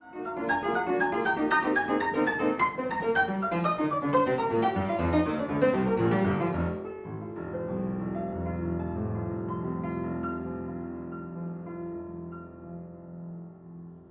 fortepiano